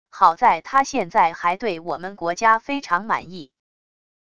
好在他现在还对我们国家非常满意wav音频生成系统WAV Audio Player